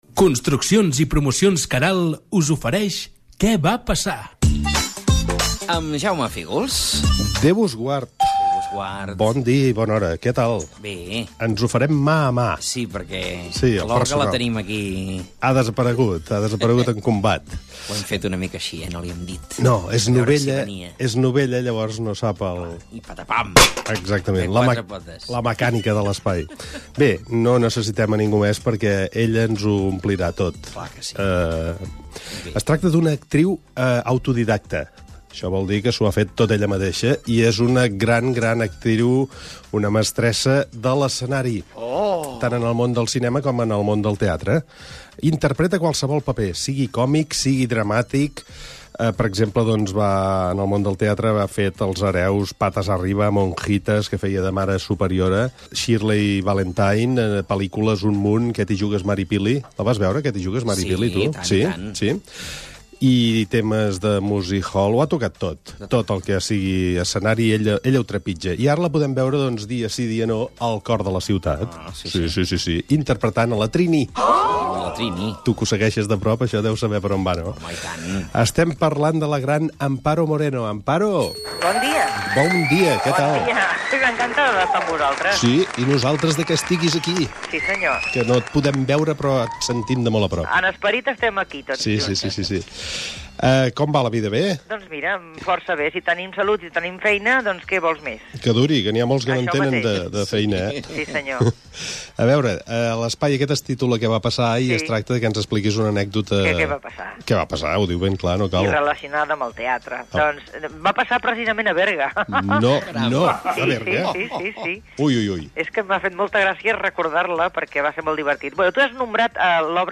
Espai "Què va passar?". Careta del programa amb publicitat i entrevista telefònica a l'actriu Amparo Moreno
Entreteniment